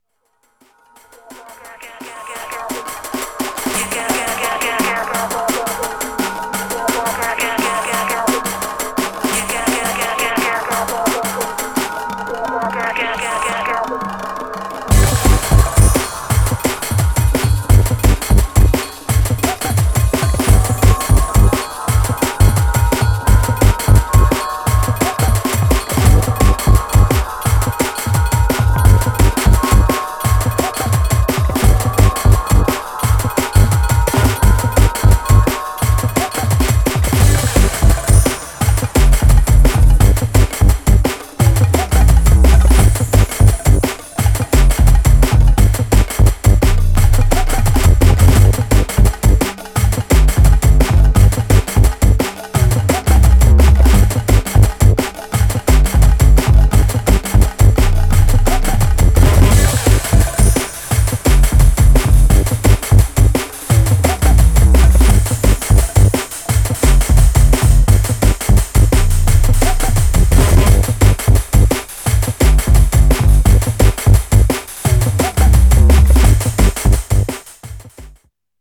Styl: Drum'n'bass, Lounge, Breaks/Breakbeat